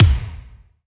STAB RM.wav